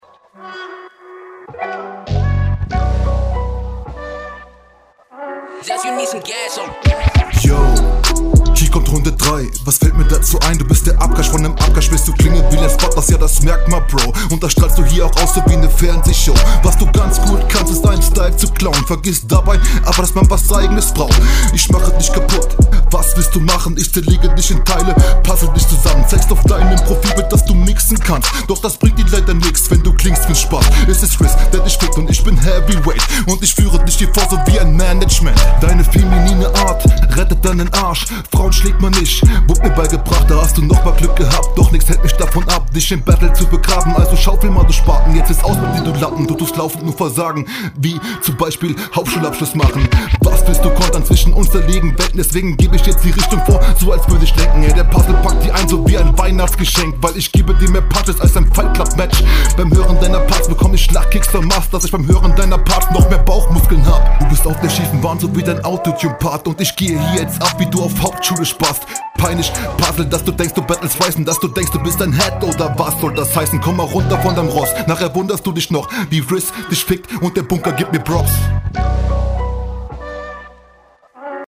Ou die Doubles sind off am Anfang.
Du bist im zweiten Part zu leise abgemischt.